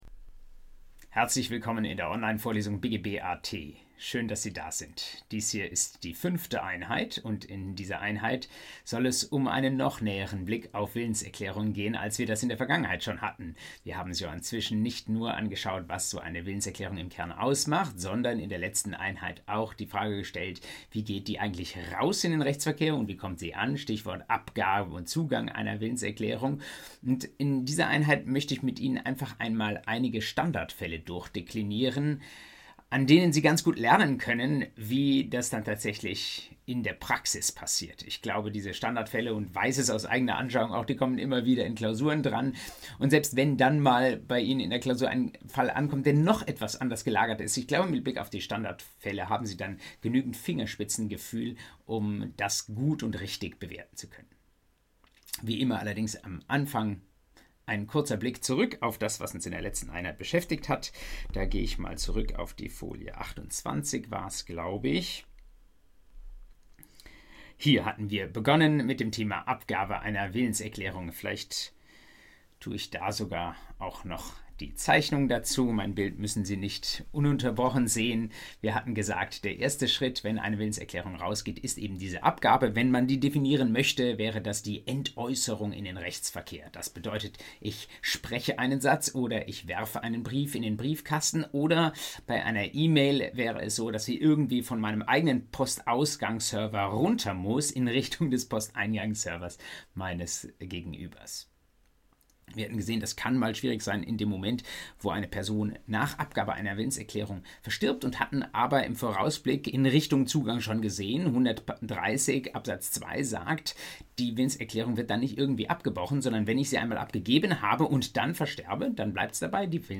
BGB AT Folge 5: Standardfälle zum Vertragsschluss ~ Vorlesung BGB AT Podcast